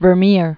(vər-mîr, -mâr), Jan 1632-1675.